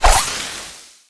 crow_throw_01.wav